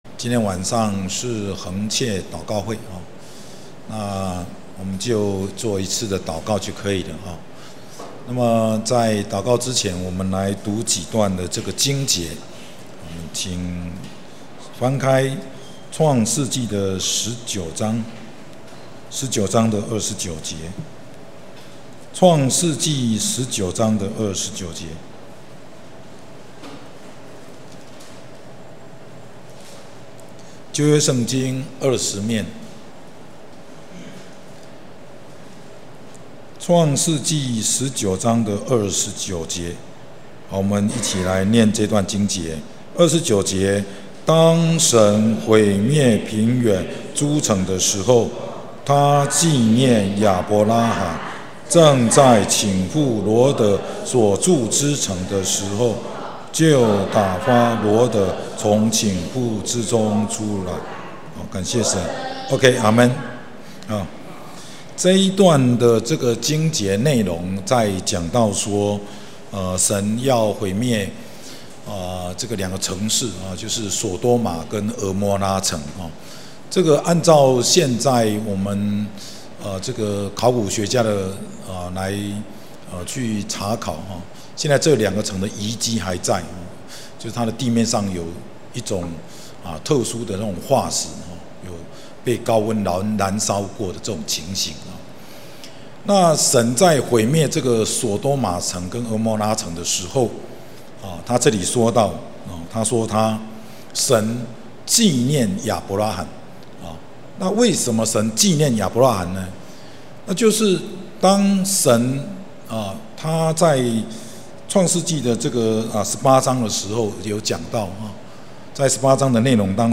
2019年11月份講道錄音已全部上線